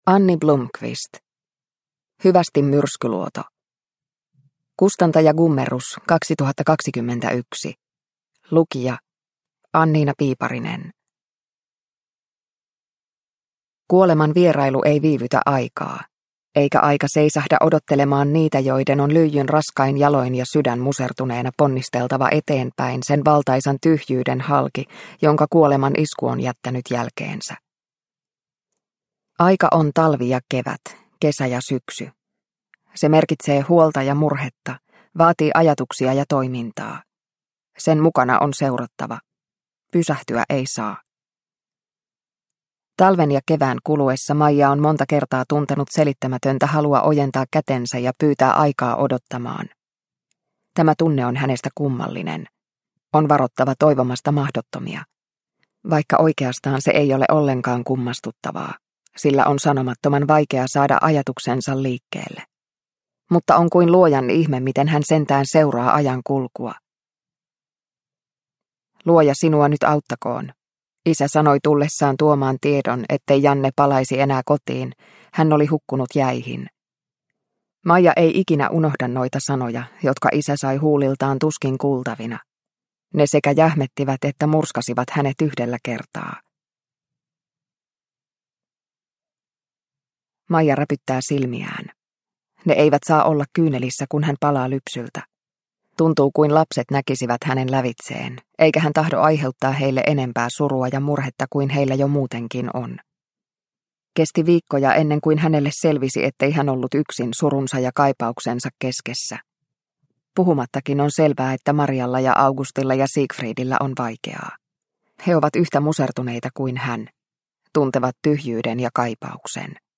Hyvästi Myrskyluoto – Ljudbok – Laddas ner